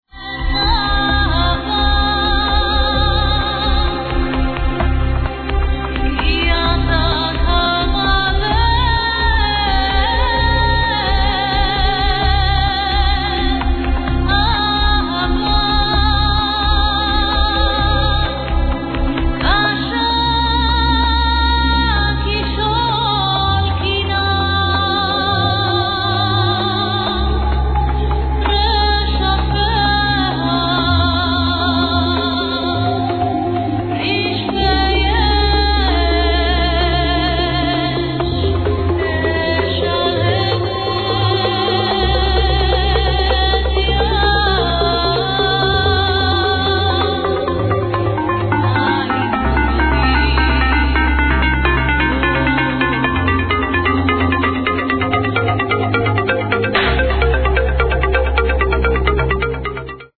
Proggy - with sweet voice